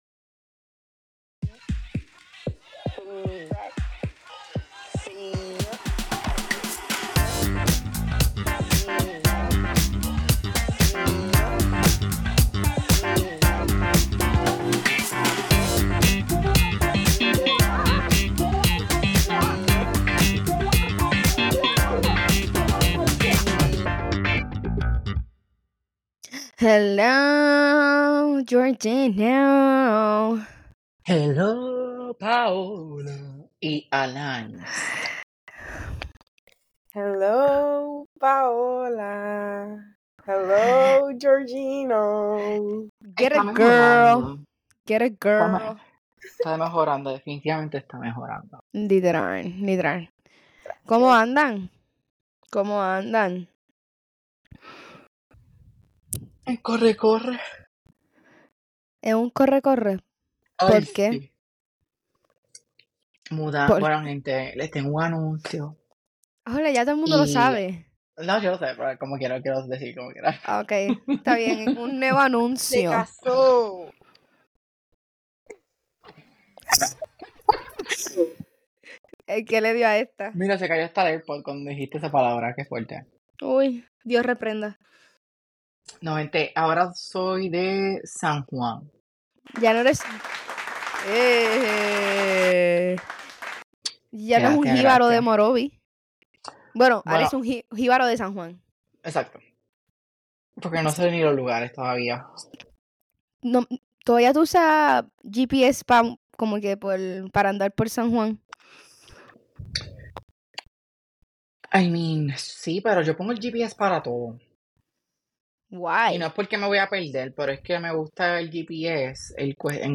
A podcast of two best friends talking chit